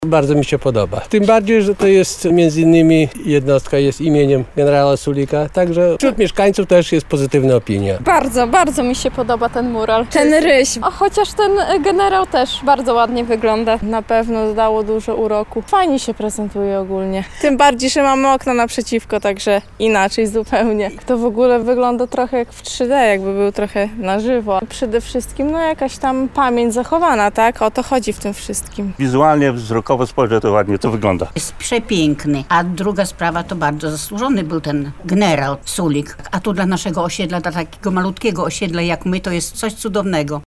Mieszkańców ulicy Kawaleryjskiej o mural pytała
14-sonda-mural-Sulik.mp3